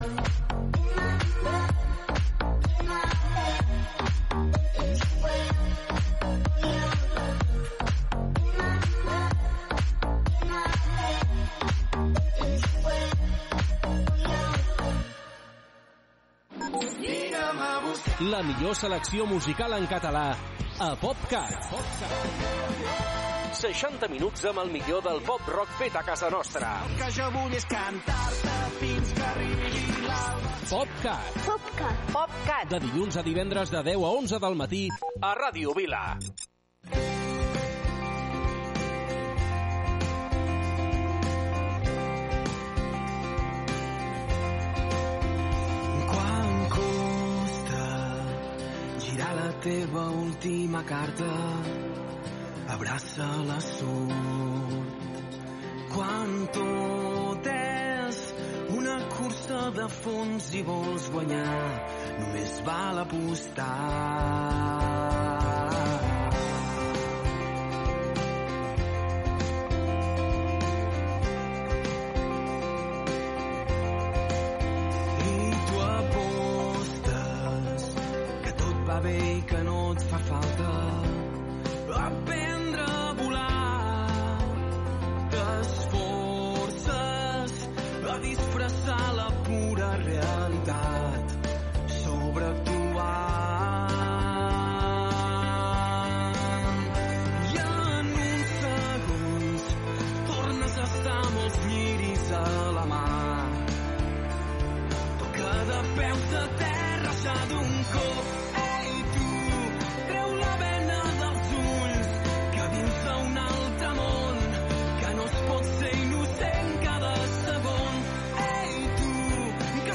POP Cat. 60 minuts de la millor música feta a casa nostra.